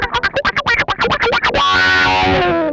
FAST TICK.wav